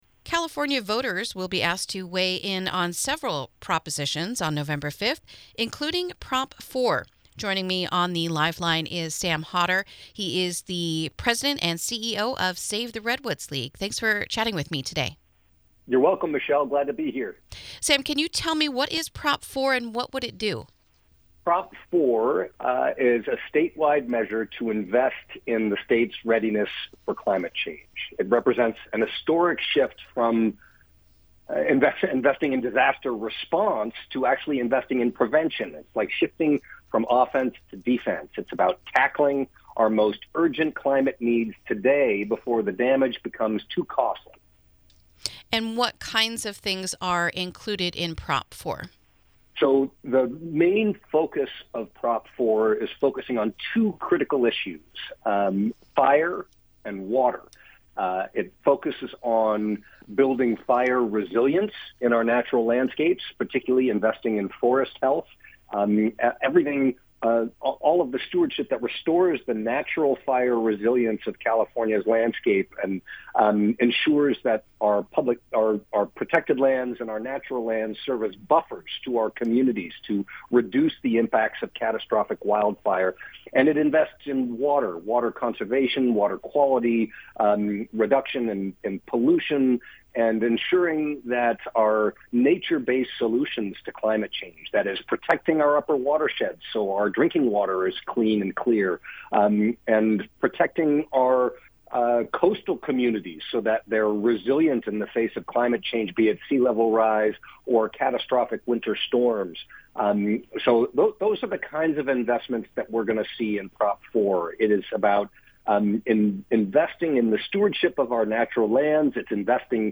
INTERVIEW: California Firefighters Support Prop 4